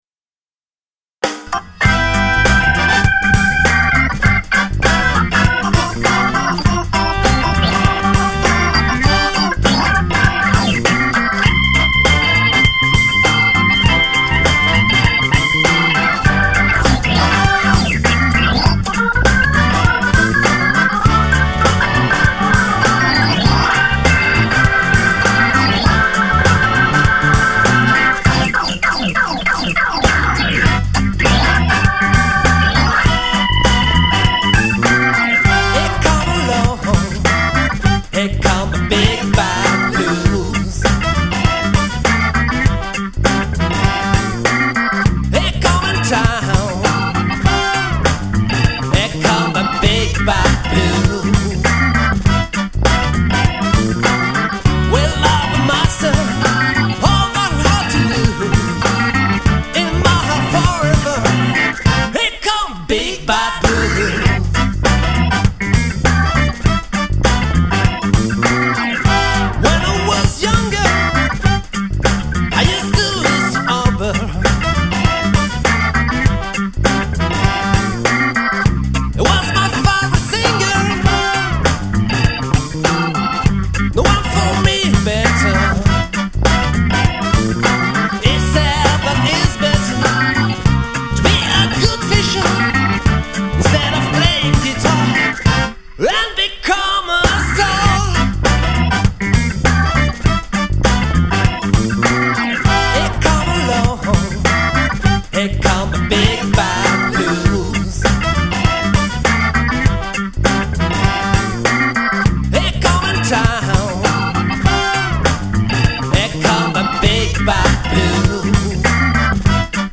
Il a raison l'aut', votre son est vraiment pro = gros son, super en place.
De ce point de vue, l'attaque du solo d'orgue est vraiment bien.
Les choeurs sont un peu maltraités aussi : ils ont un grain trop proche des cuivres.
C'est seulement des maquettes pour que tout le monde puisse bosser.